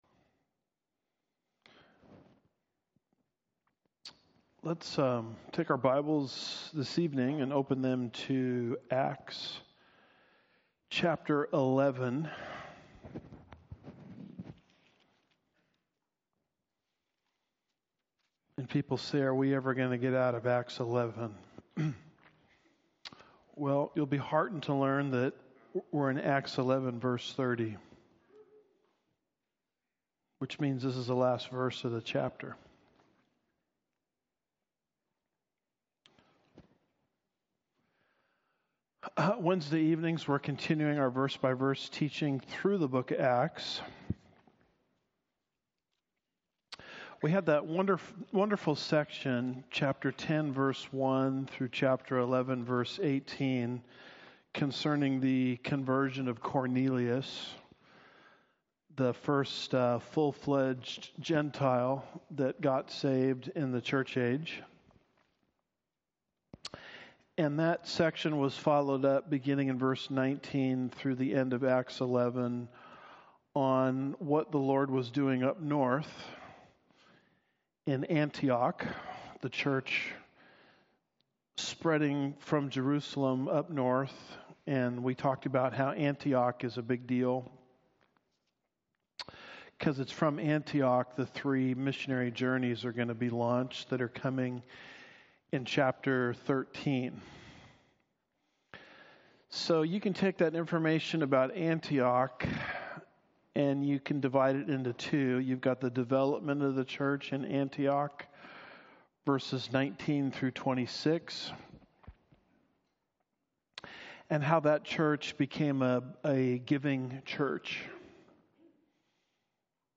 Acts 068 – Why Elder Rule? Home / Sermons / Acts 068 – Why Elder Rule?